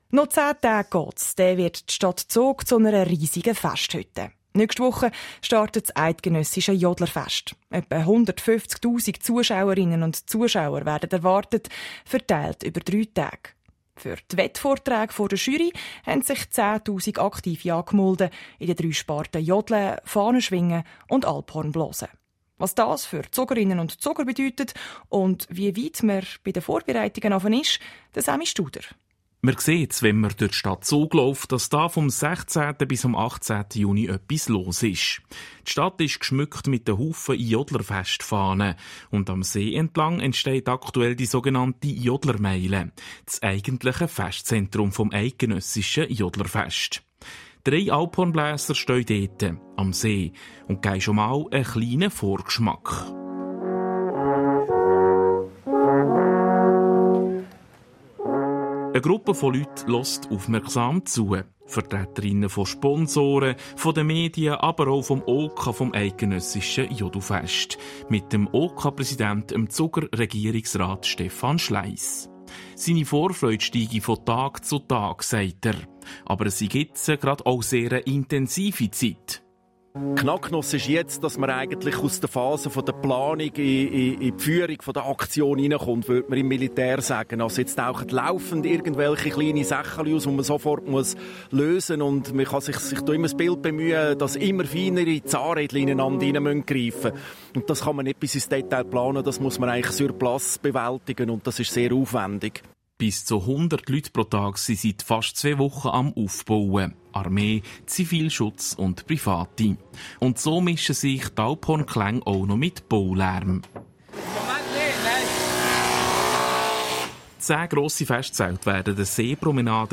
Report SRF Regionaljournal Zentralschweiz 06/23